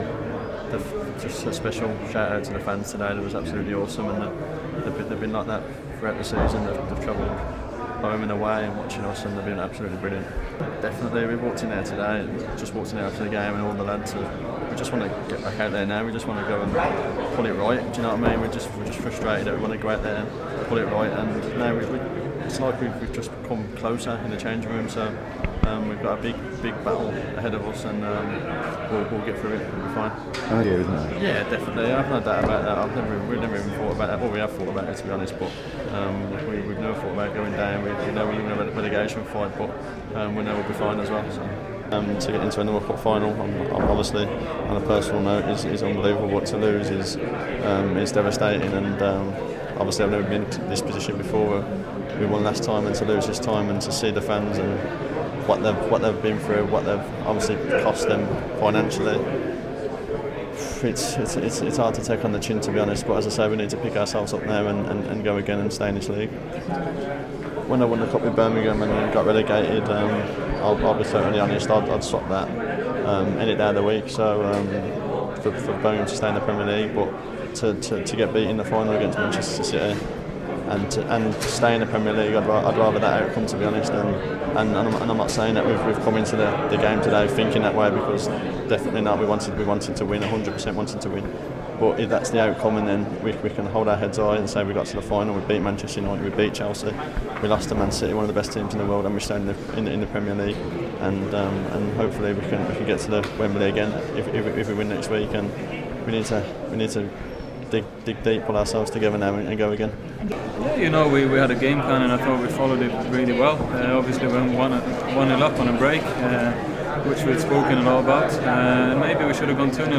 Gus Poyet and his players talk to the media after thier Capital One Cup Final loss to Manchester City. Craig Gardner and Phil Barsdley talk about the future for Sunderland, Poyet shares his feelings on the game and Larsson hails Yaya Toure's equalising goal.